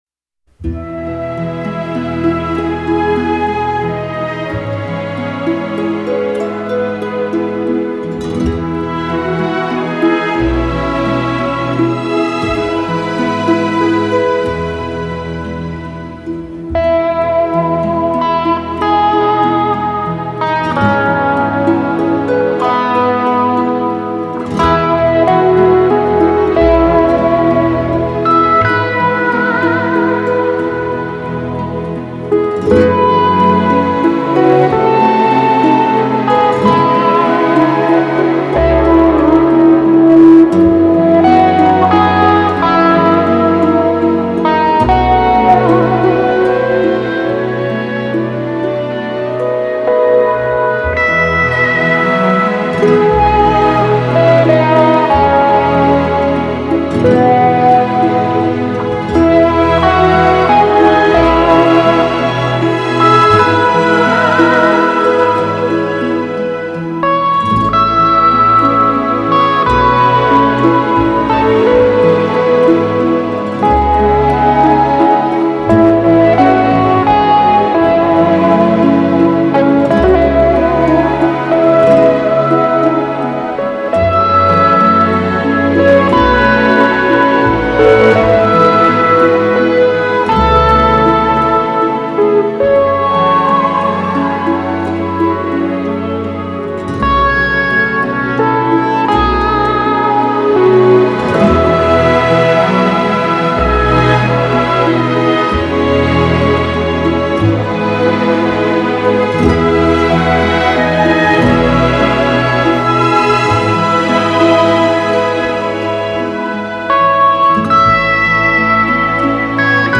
Жанр: Classic|Relax